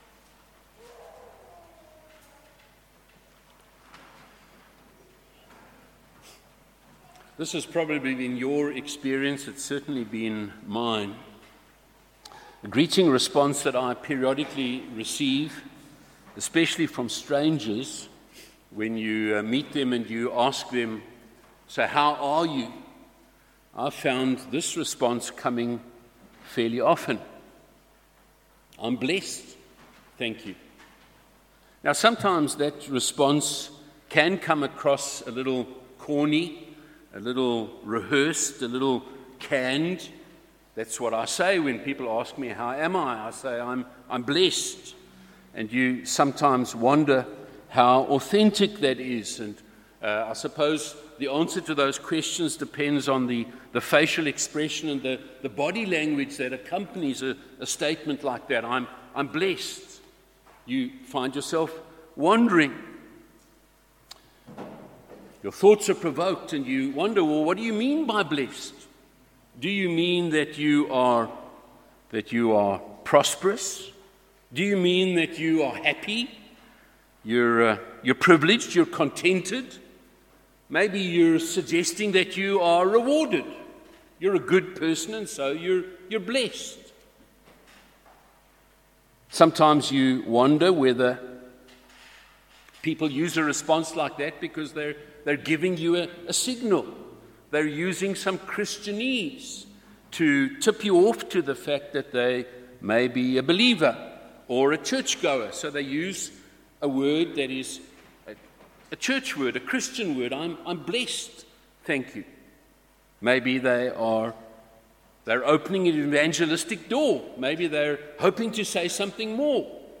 Sermons under misc. are not part of a specific expositional or topical series.